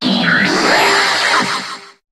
Cri de Cancrelove dans Pokémon HOME.